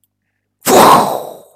wind.mp3